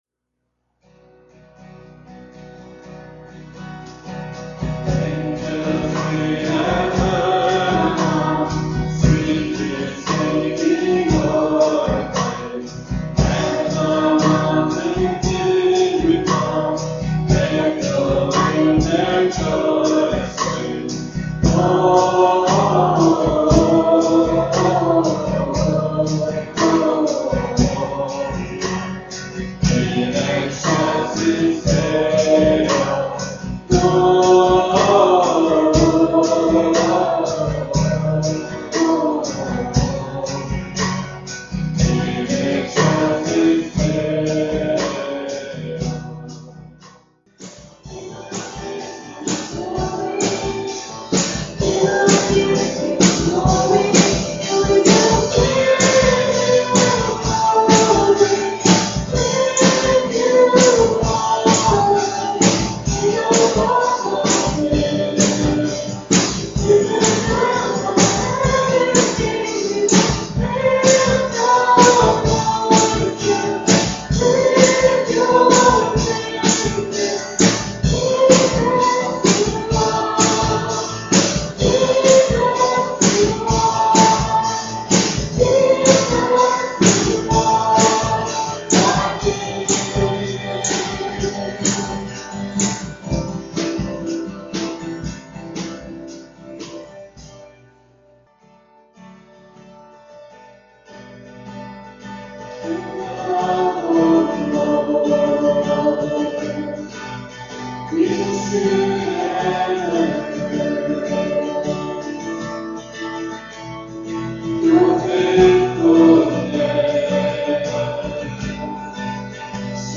PLAY Christmas Stories: The Journey, December 18, 2011 Scripture: Luke 2:1-7. Message